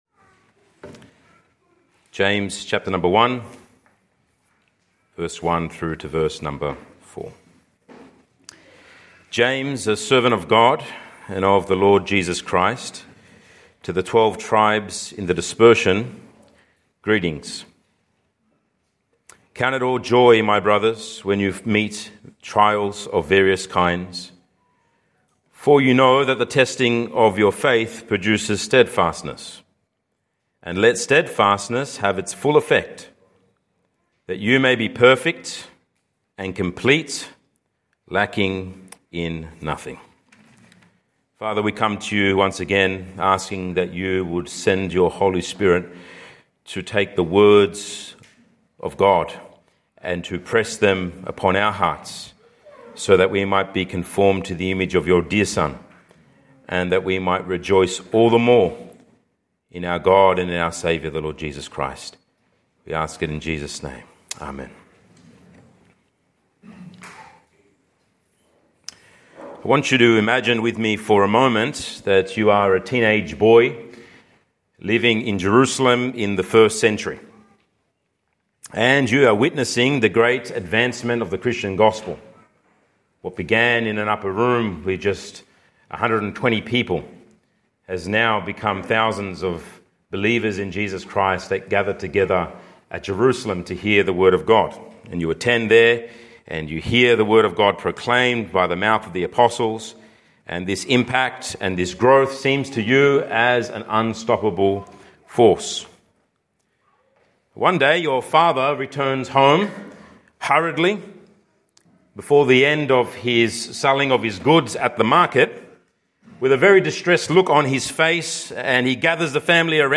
sermon.mp3